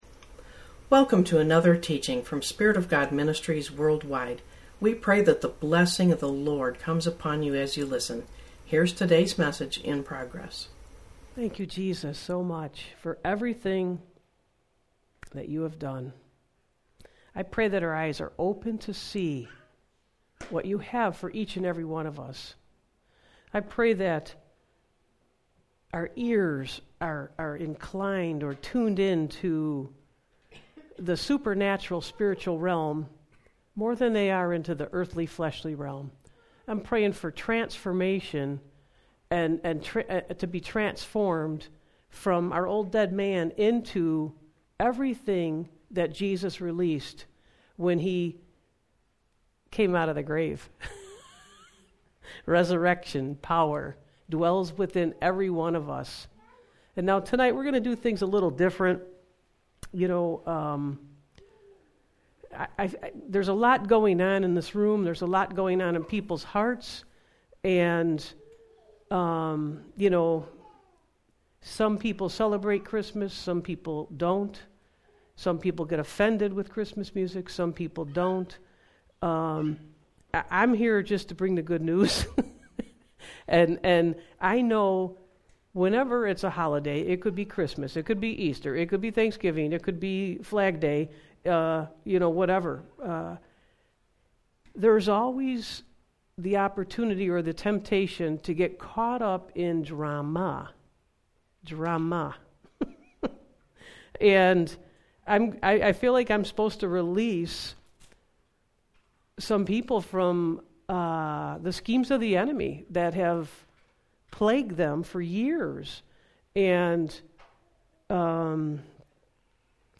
Sermons | Spirit Of God Ministries WorldWide